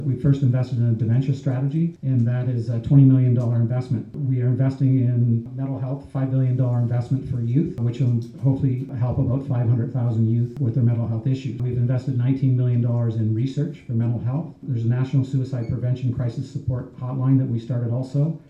A large group of citizens at St. Joseph’s Parish was engaged in the discussion for close to two hours.